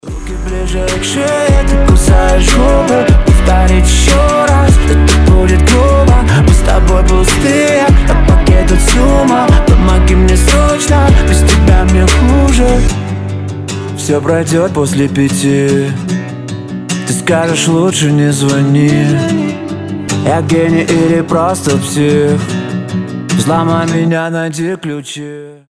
• Качество: 320, Stereo
гитара
мужской голос
Хип-хоп